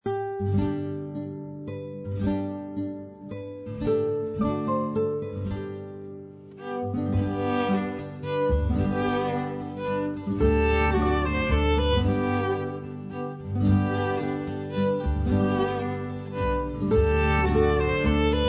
32 Weihnachtslieder aus verschiedenen Ländern für 1-2 Violen
Besetzung: 1-2 Violen mit CD